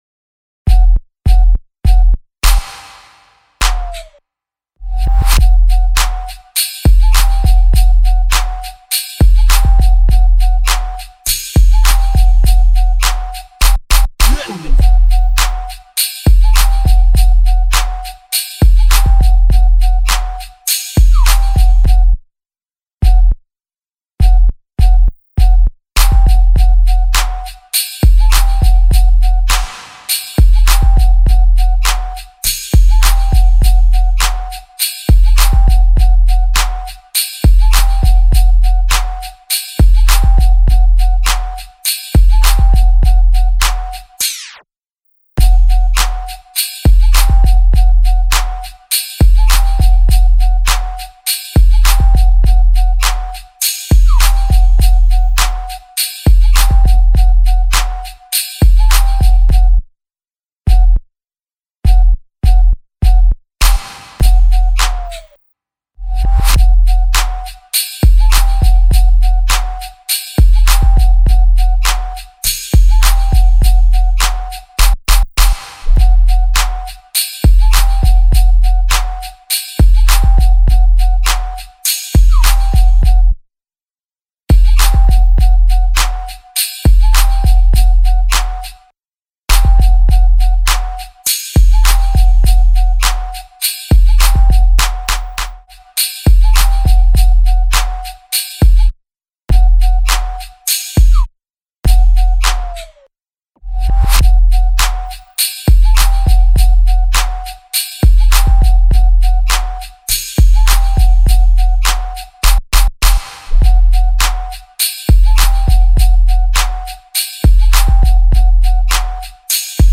official instrumental
2020 in Hip-Hop Instrumentals